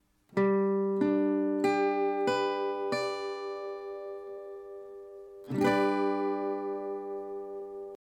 G-Dur (Barré, A-Saite)
Hier ist es besonders wichtig, die E-Saite mit der Spitze des Zeigefingers abzudämpfen.
G-Dur-Barre-A.mp3